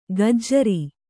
♪ gajjari